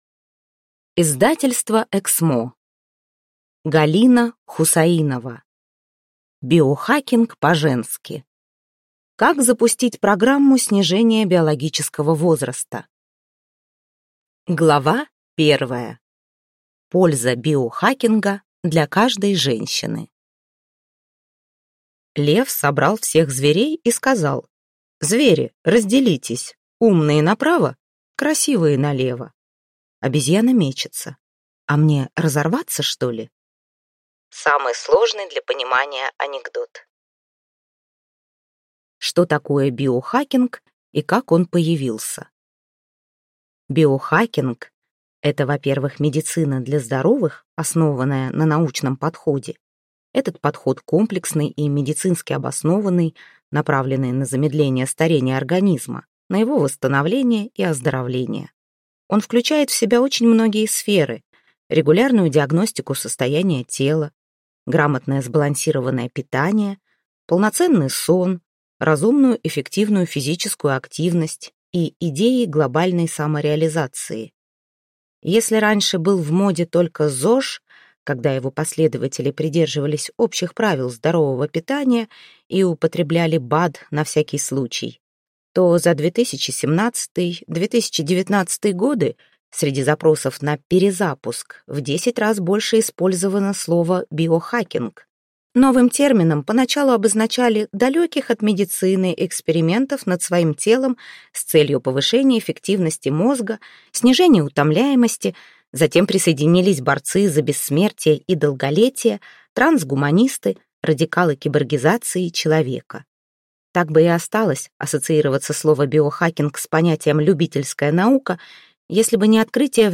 Аудиокнига Биохакинг по-женски. Как запустить программу снижения биологического возраста | Библиотека аудиокниг